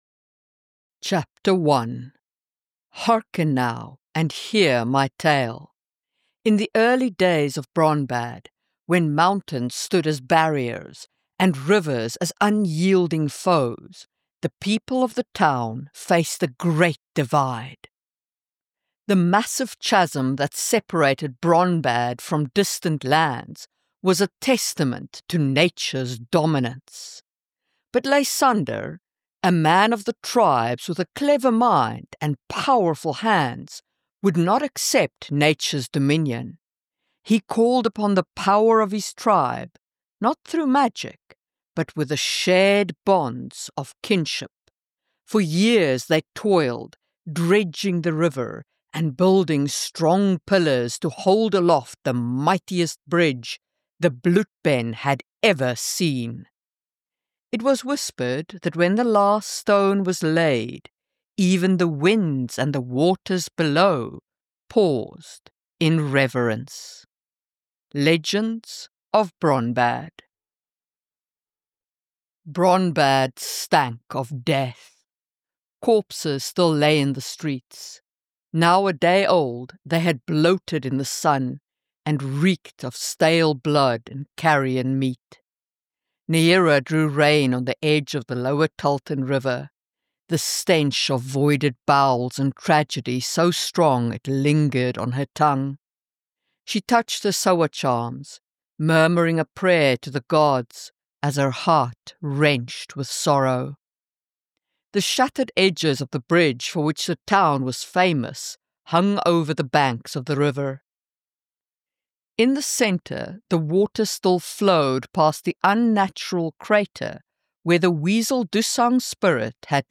Keeper of the Gate (Audio Book) | Marie Mullany